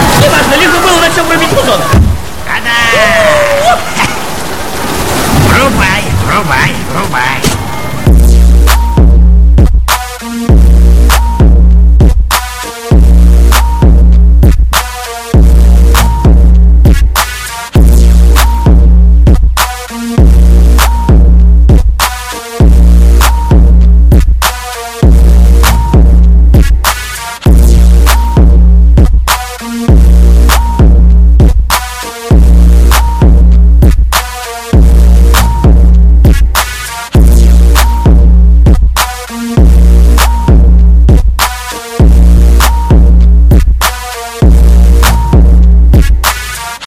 Клубные рингтоны Загрузил